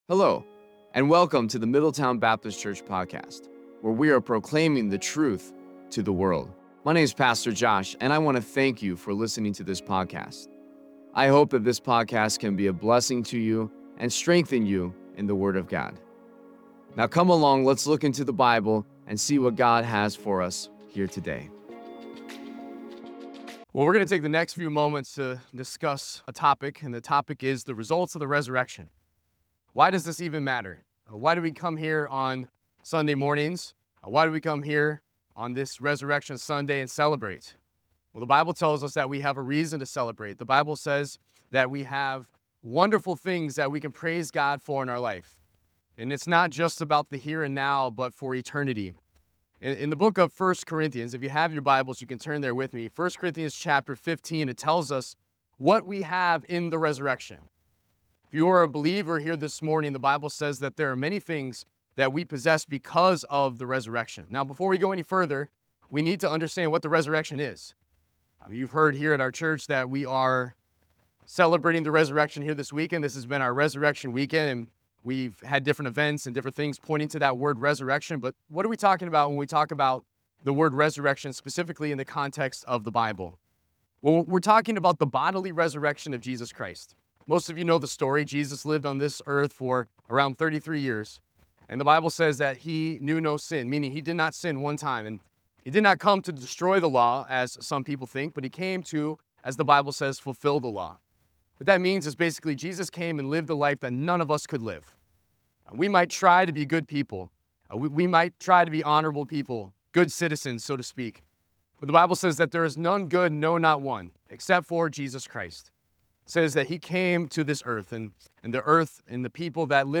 The sermon begins with a declaration of the significance of the resurrection, emphasizing that it is not merely a historical event but a transformative occurrence that provides hope and assurance to the faithful.
Easter-Sunday.mp3